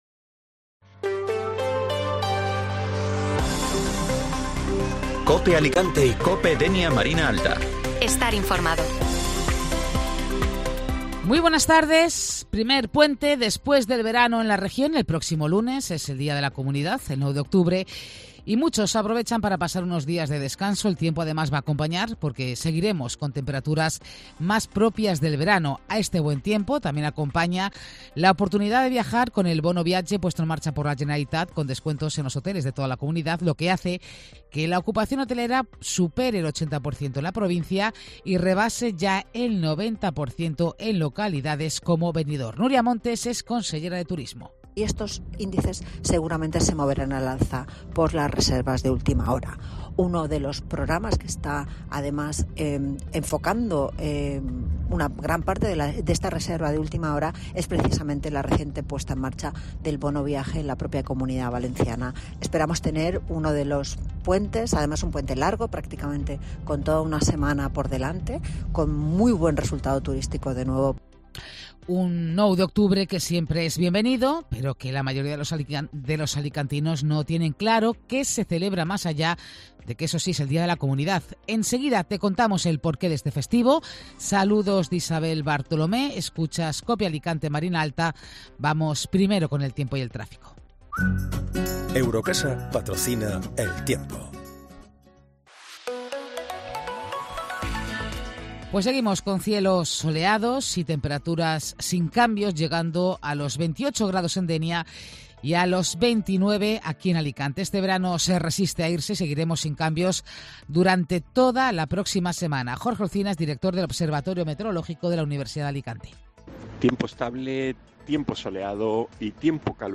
Informativo Mediodía Cope Alicante (Viernes 6 de Octubre)